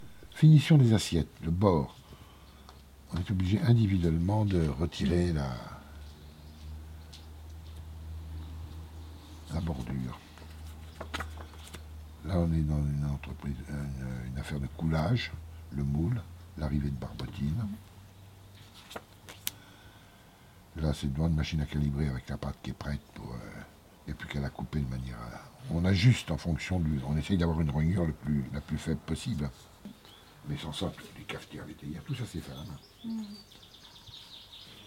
Cet article s’appuie sur une collecte audiovisuelle de la mémoire de l’industrie de la ville de Vierzon donnant lieu à la création d’un site Internet.